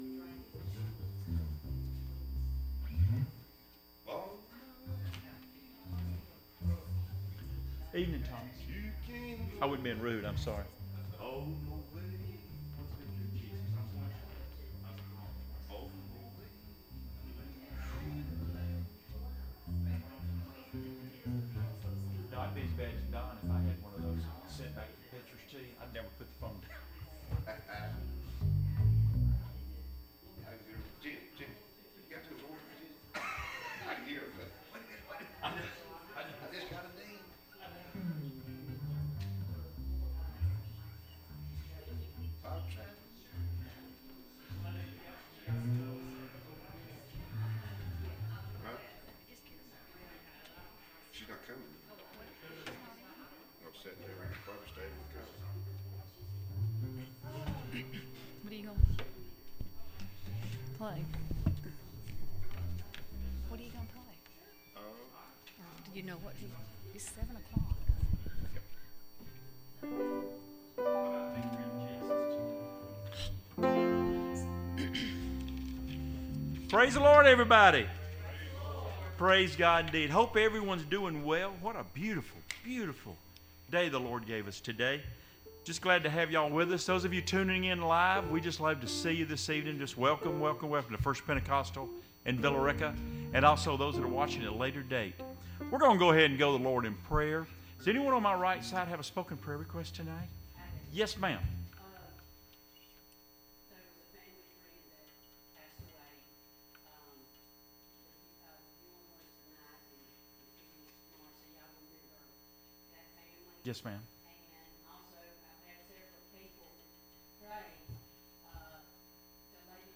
"Jeremiah 17:7-8" Service Type: Wednesday Evening Services « What Would You Ask of Jesus?